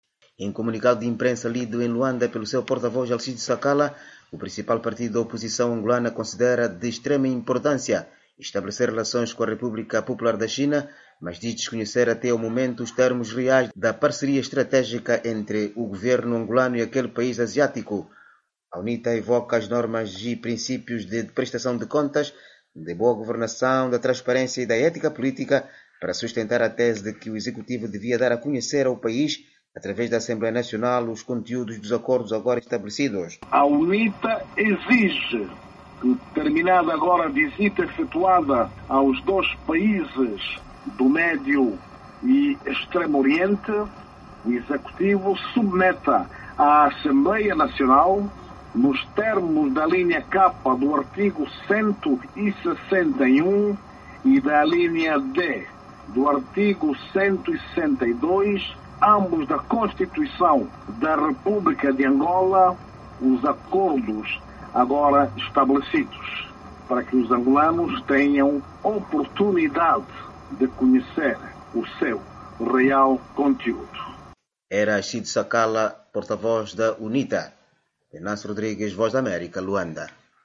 Em comunicado de imprensa lido em Luanda pelo seu porta-voz, Alcides Sakala, o partido galo negro considera de “extrema importância” estabelecer relações com a República Popular da China, mas diz desconhecer até ao momento os termos reais da parceria estratégica entre Luanda e Pequim.